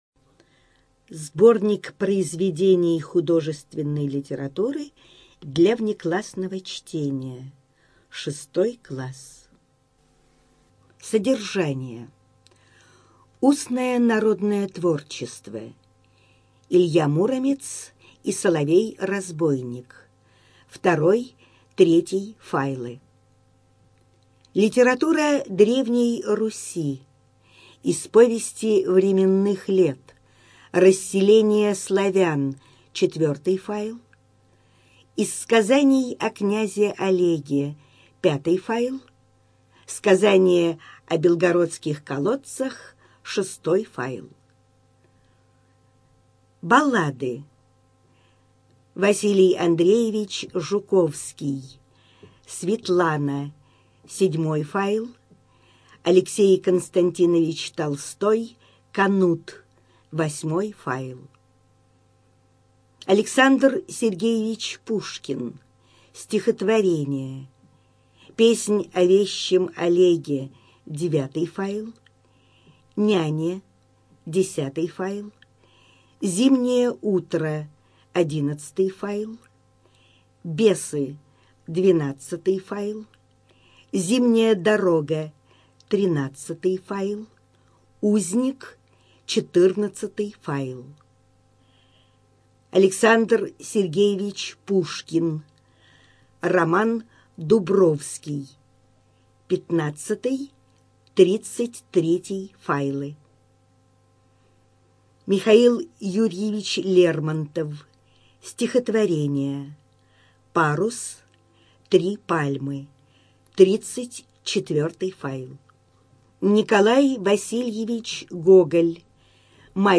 Студия звукозаписиКурская областная библиотека для слепых